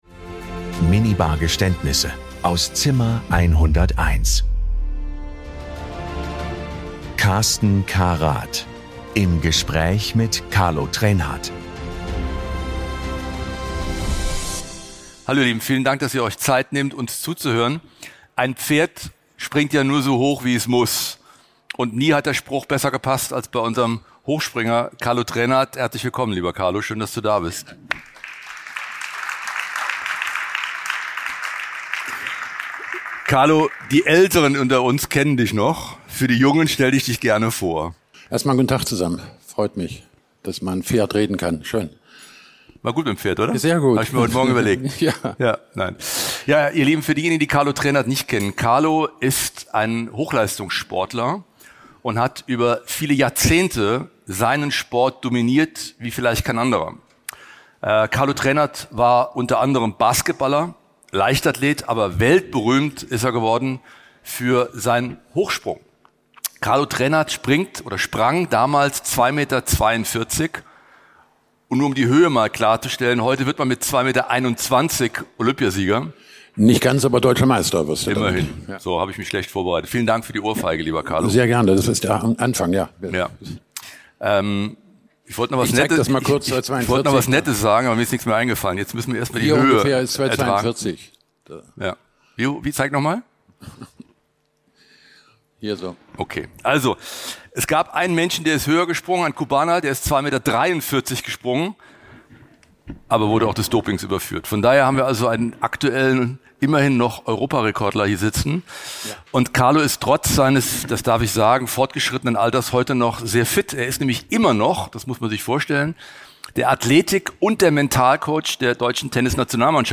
Heute gibt er seine Erfahrungen weiter und spricht über Leistung, Fokus und den Umgang mit Druck. Ein Gespräch über seinen außergewöhnlichen Karriereweg und darüber, was es braucht, um über Jahre hinweg auf höchstem Niveau zu bestehen.